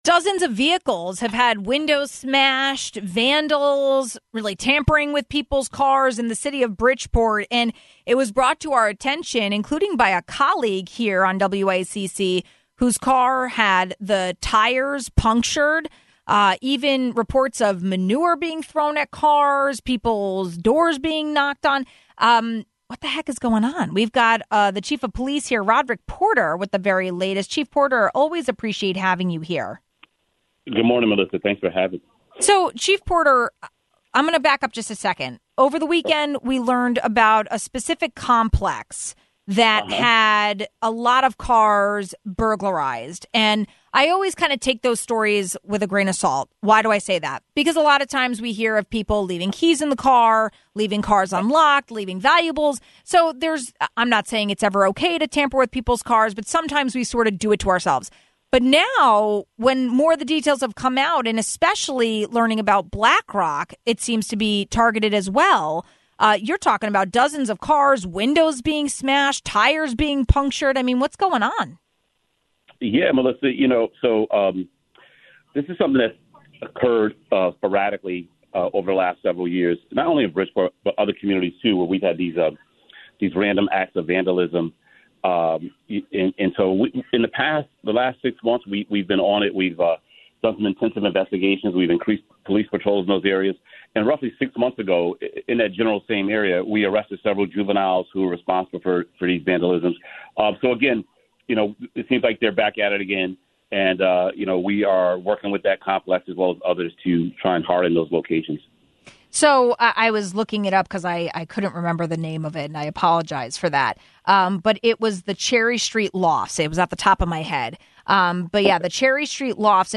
Dozens of cars have apparently been broken into and messed with. We got the latest on this problem from Bridgeport Police Chief Roderick Porter.